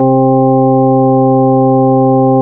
MELLOW 2.wav